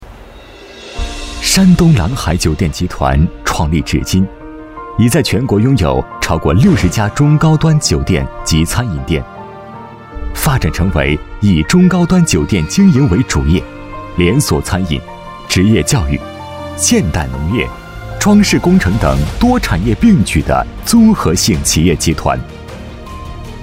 宣传片配音